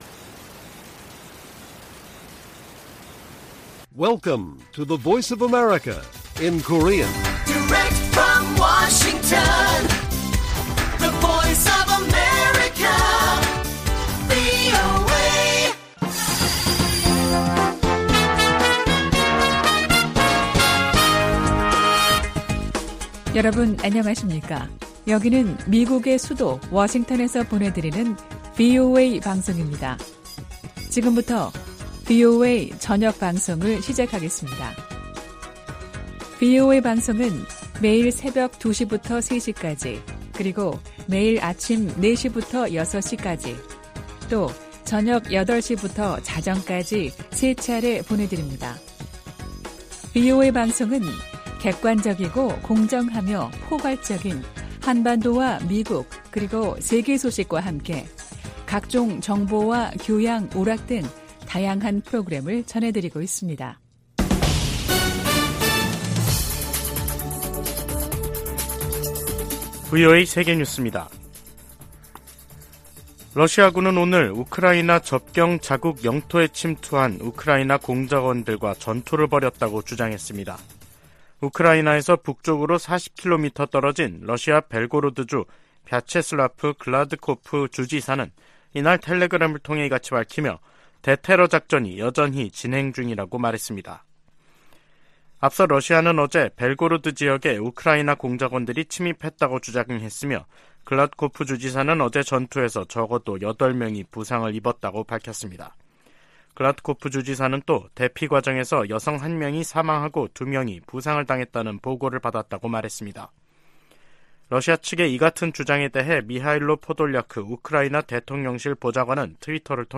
VOA 한국어 간판 뉴스 프로그램 '뉴스 투데이', 2023년 5월 23일 1부 방송입니다. 조 바이든 미국 대통령은 일본 히로시마 G7 정상회의로 미한일 3자 협력이 새 차원으로 격상됐다고 말했습니다. G7을 계기로 미한일 공조가 강화되면서 북한, 중국, 러시아의 외교, 안보, 경제의 밀착이 구체화 될 것이라고 전문가들이 내다보고 있습니다. 한국과 유럽연합(EU) 정상이 북한의 도발 행위를 강력히 규탄하며 비핵화 대화에 복귀할 것을 촉구했습니다.